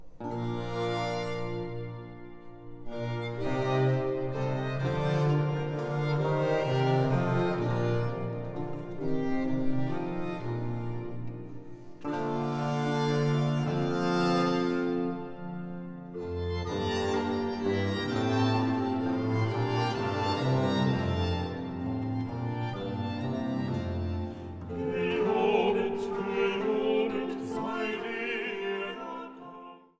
Sopran
Altus
Tenor
Leitung und Orgel
Diese Aufnahme mit Vokal-, Instrumental- und Orgelmusik